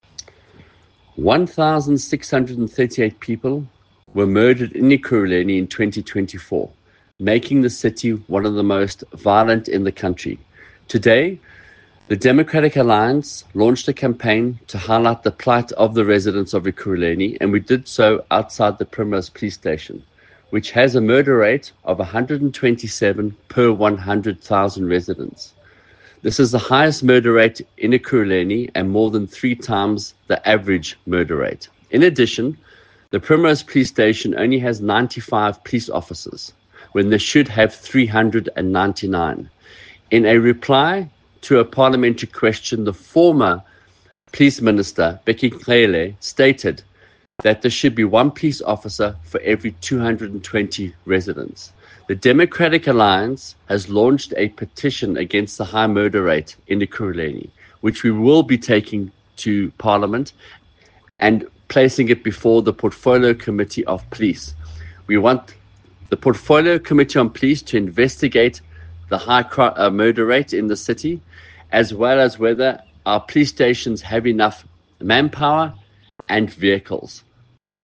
Note to Editors: Please find an English soundbite by Michael Waters MPL